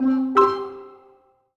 happy_collect_item_06.wav